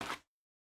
Bare Step Gravel Hard B.wav